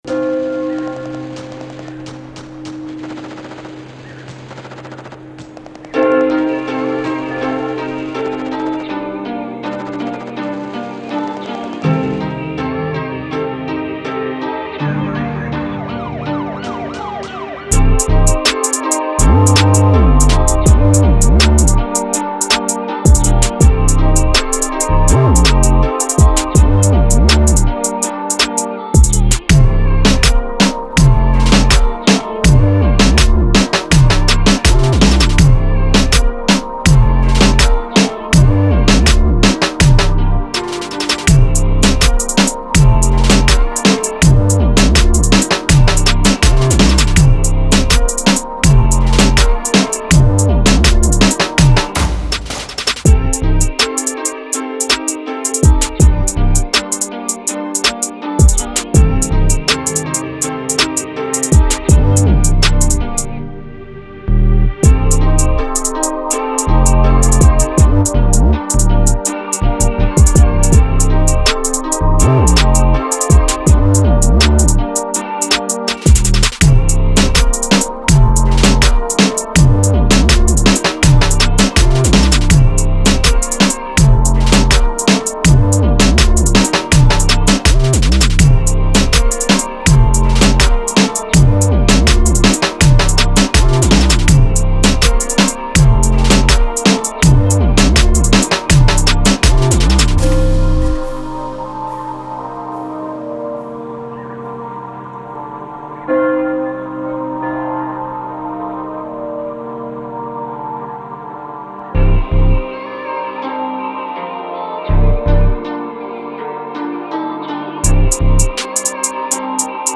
Reggaeton & Drill
Instrumental Disponible